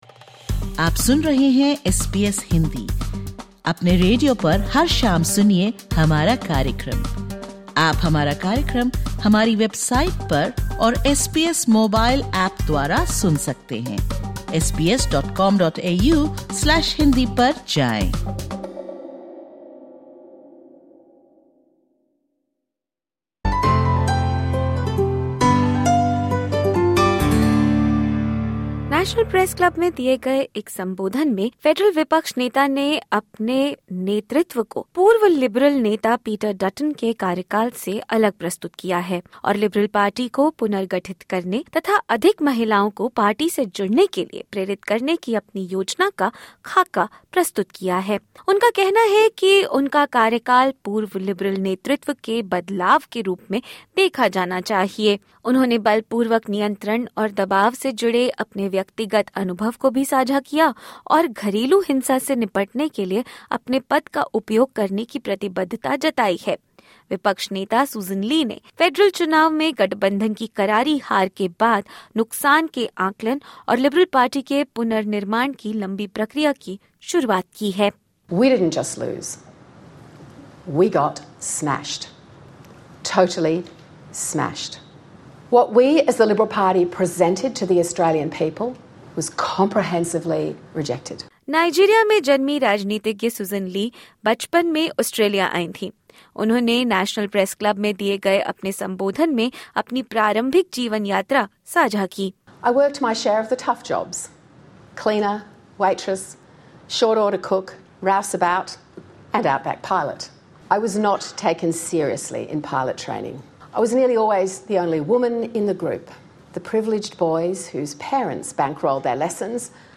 In her first National Press Club address, Opposition Leader Sussan Ley outlines a new direction for the Liberal Party—focusing on rebuilding, gender representation, and tackling domestic violence.